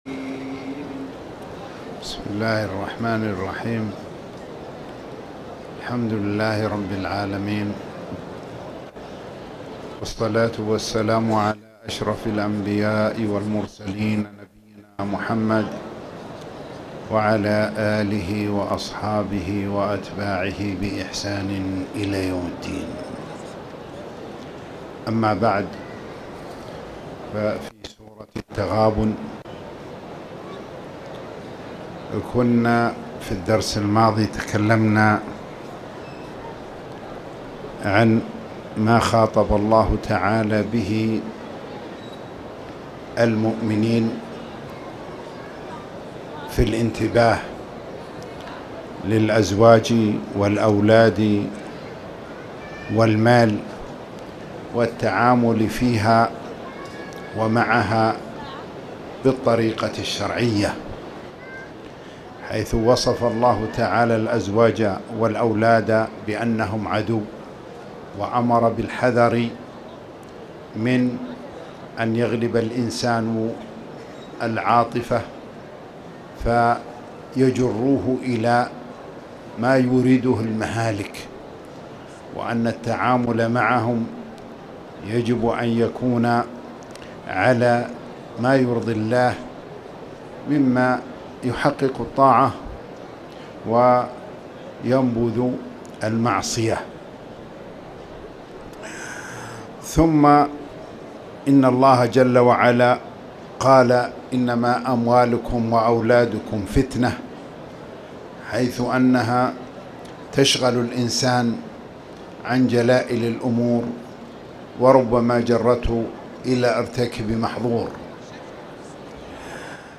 المكان: المسجد الحرام